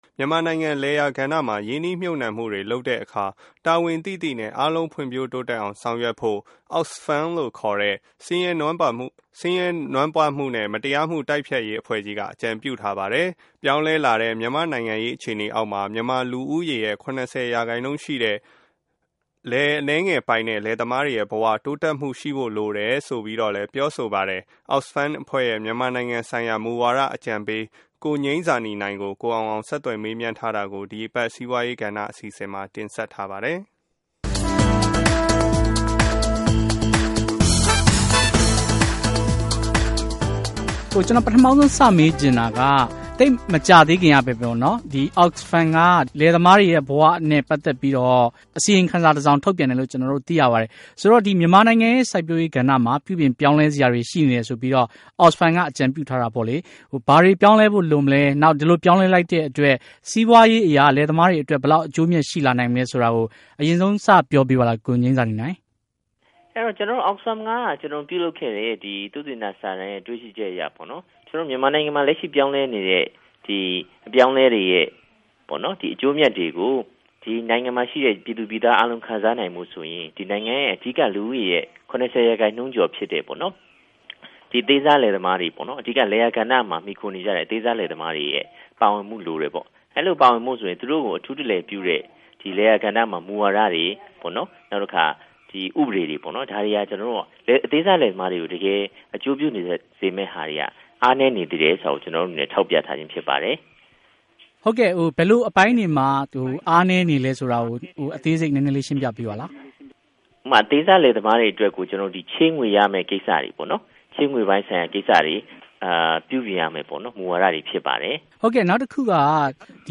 Oxfam Interview